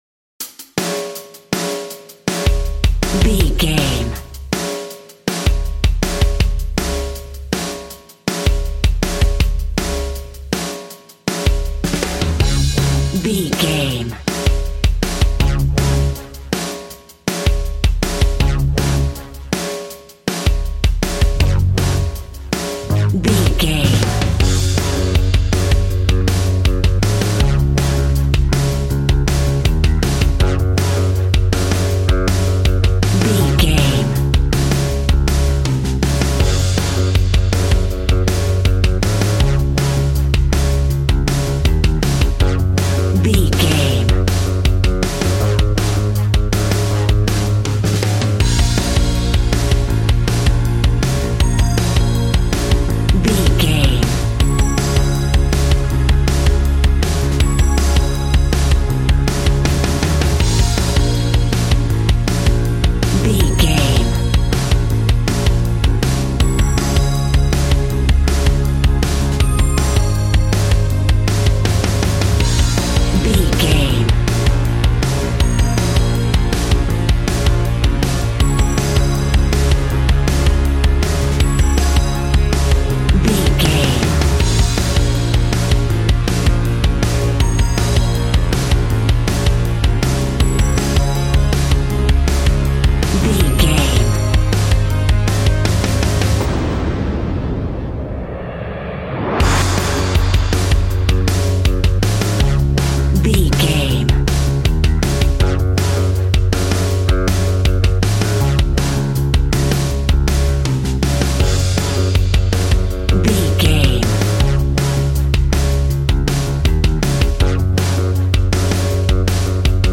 Ionian/Major
D♭
groovy
happy
electric guitar
bass guitar
drums
piano
organ